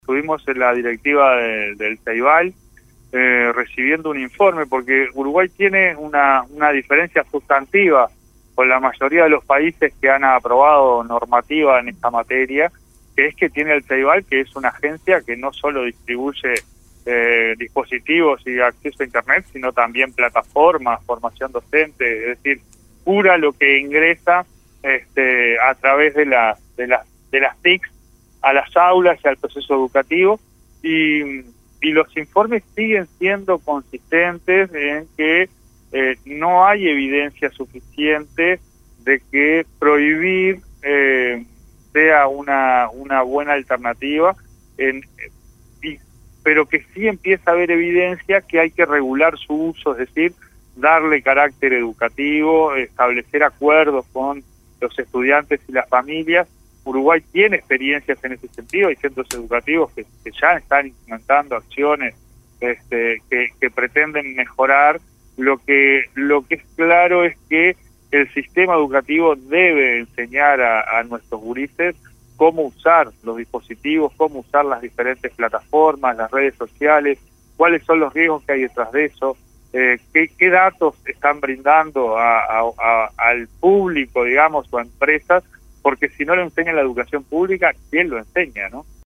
El presidente del Codicen, Pablo Caggiani, indicó en diálogo con radio Monte Carlo de Montevideo, que por el contrario aumentan las pruebas que señalan la necesidad de regular su utilización.